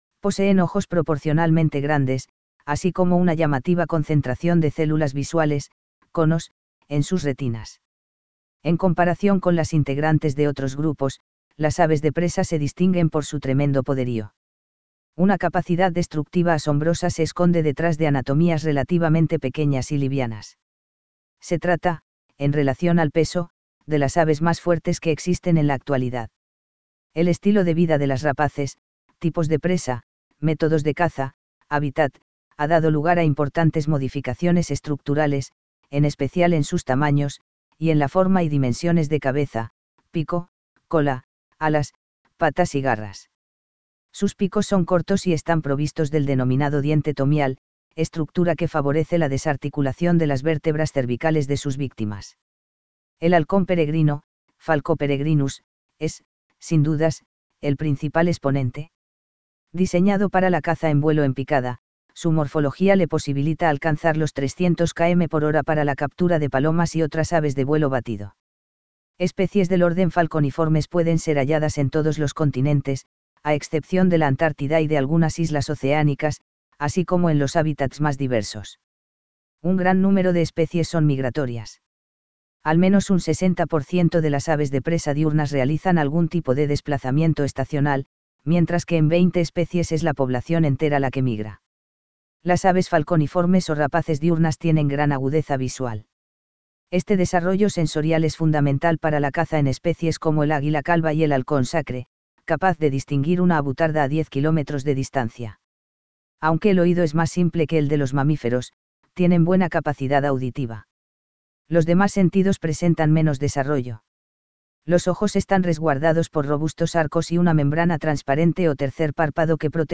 Caracara plancus plancus - Carancho.
Milvago chimango chimango - Chimango.
Milvago chimachima chimachima - Chimachima.
Spiziapteryx circumcinctus - Halconcito gris.
Falco peregrinus (cassini - tundrius) - Halcón peregrino.
Falco femoralis femoralis - Halcón plomizo.
Falco sparverius cinnamominus - Halconcito común.